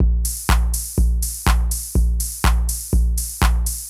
ENE Beat - Mix 6.wav